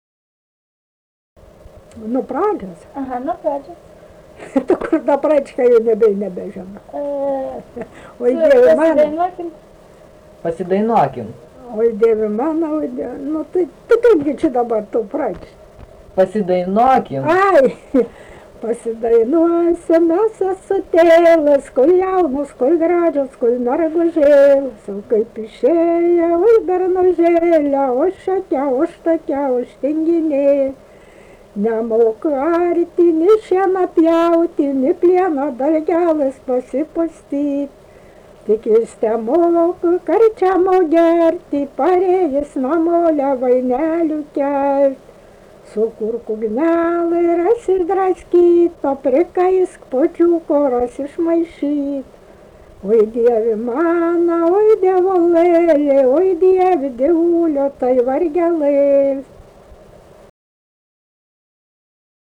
daina
Dičiūnai
vokalinis